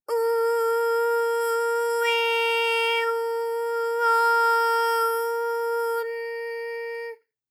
ALYS-DB-001-JPN - First Japanese UTAU vocal library of ALYS.
u_u_e_u_o_u_n.wav